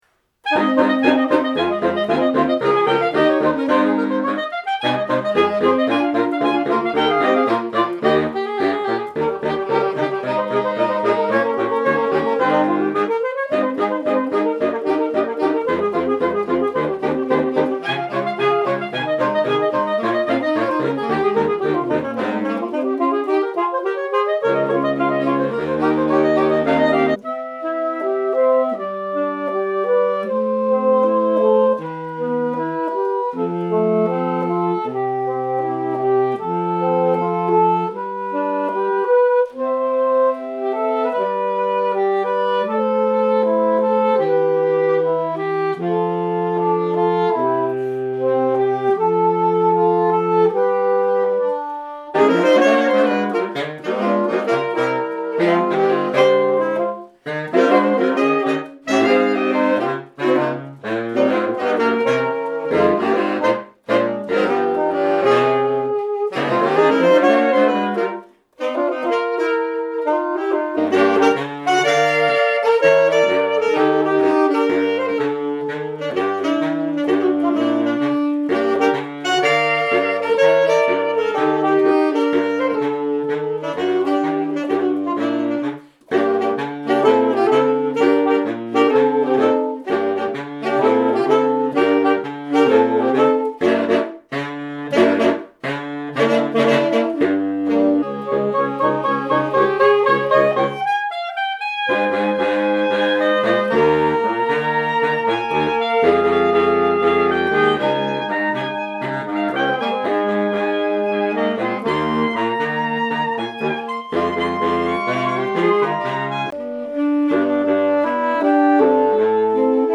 Saxophonix are an exciting and versatile Saxophone Quartet, offering several packages to suit your needs.